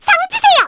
Luna-Vox_Victory_01_kr.wav